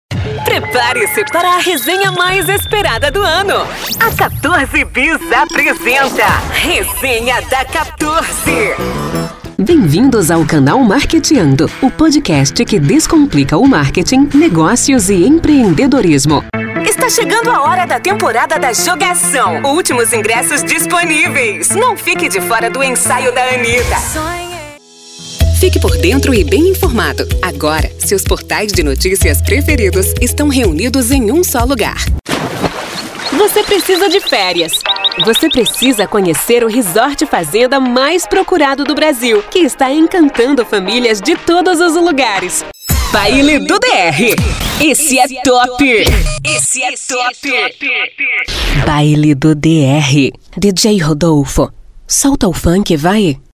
Demo Secundario :
Animada